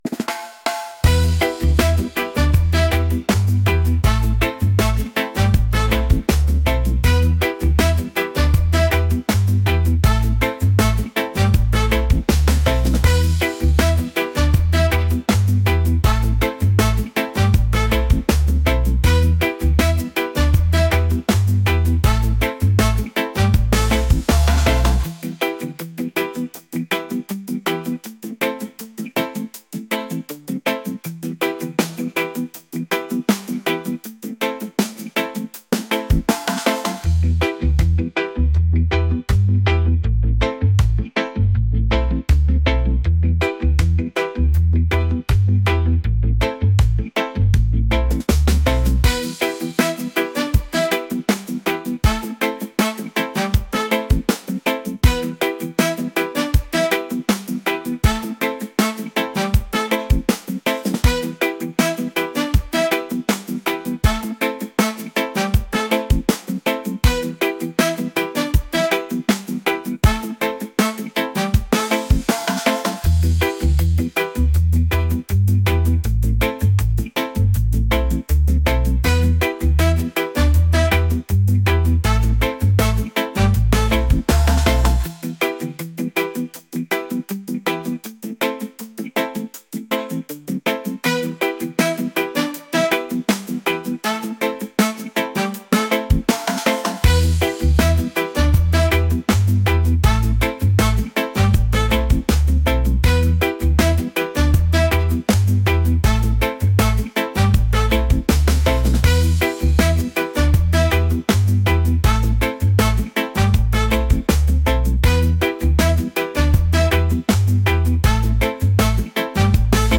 reggae | funk | soul & rnb